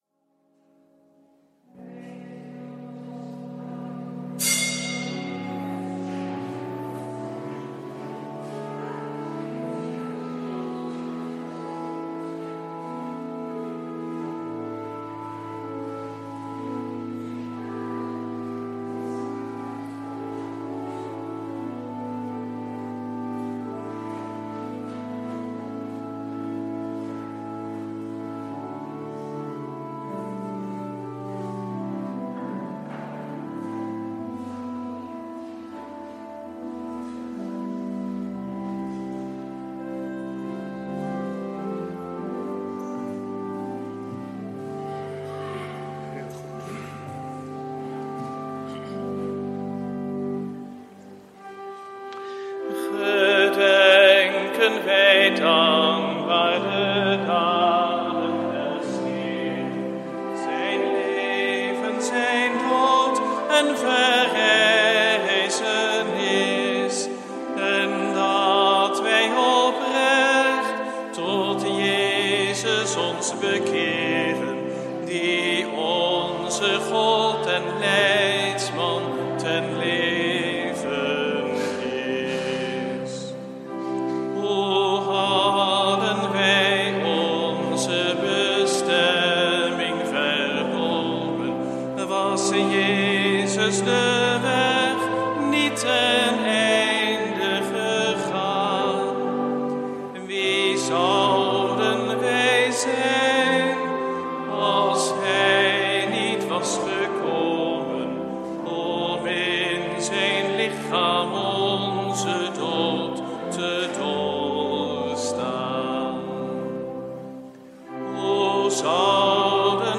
Lezingen
Eucharistieviering beluisteren vanuit De Goede Herder te Wassenaar (MP3)